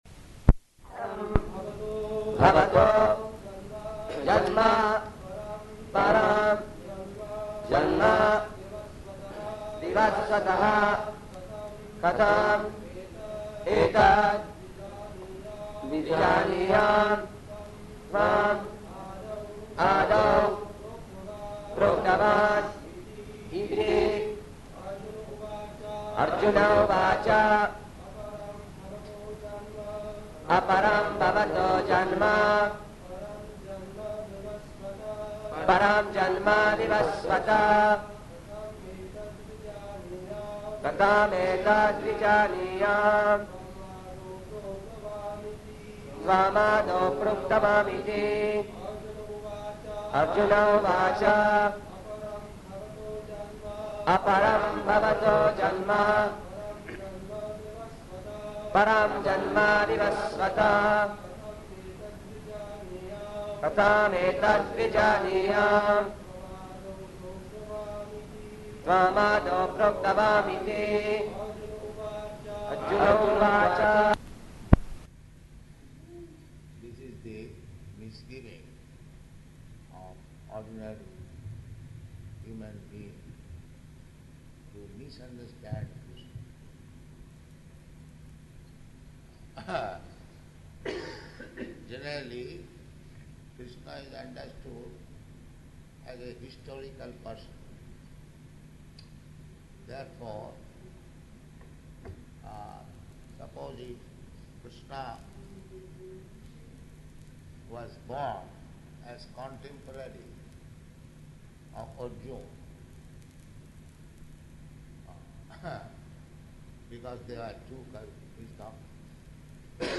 Location: Vṛndāvana
[poor recording]
[devotees repeat]